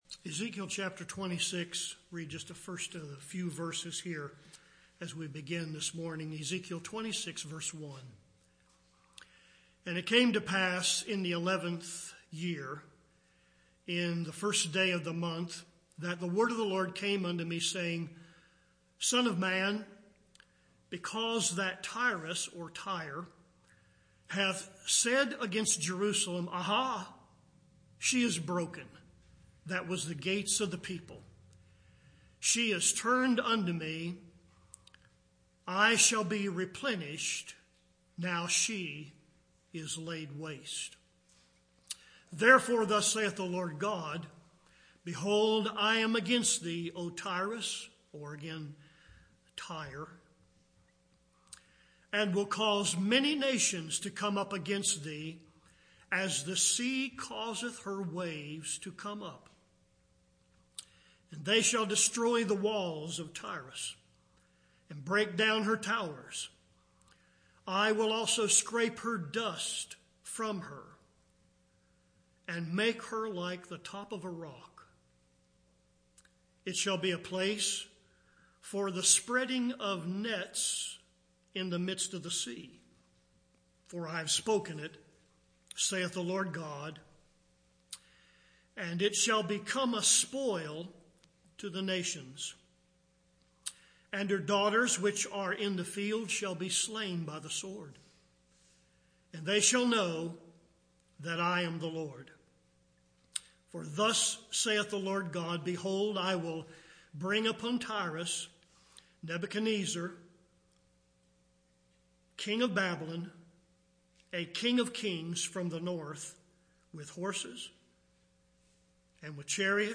Grace Fellowship Baptist Church, Arden, NC